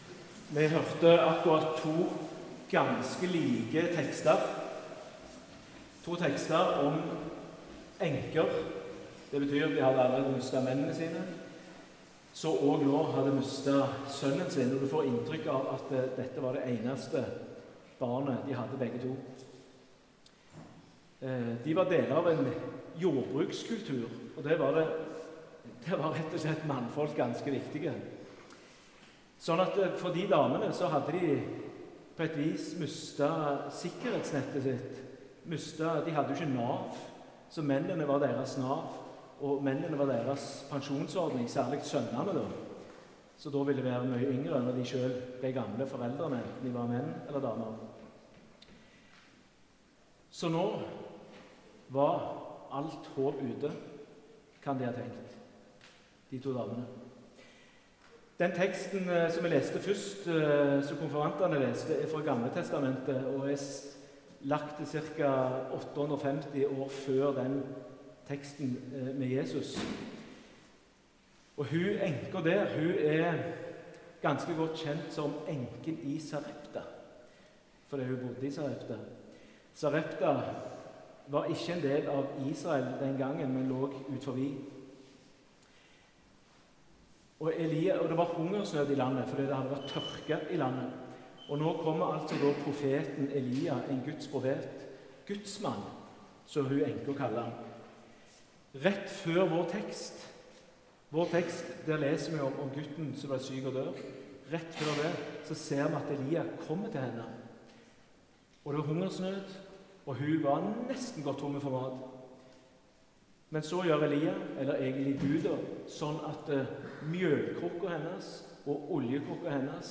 Utdrag fra talen